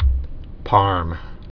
(pärm)